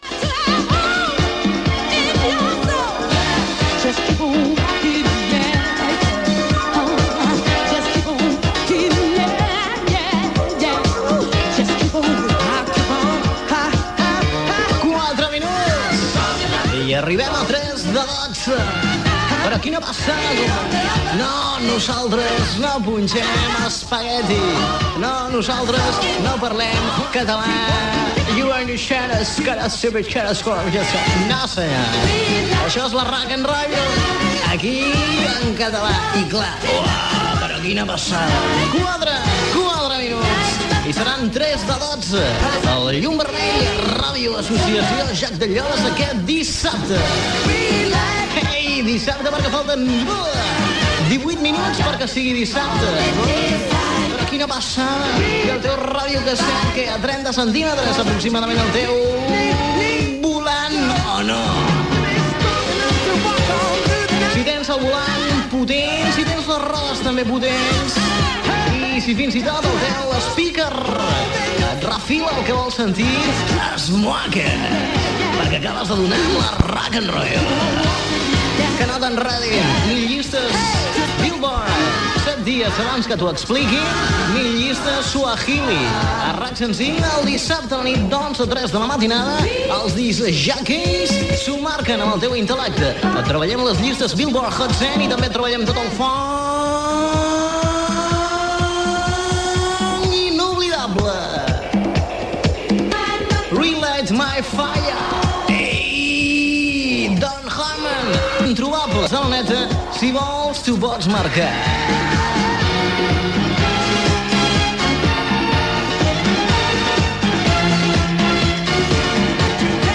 Tema musical, hora, identificació de l'emissora i del programa, comentaris diversos, tema musical
Musical
FM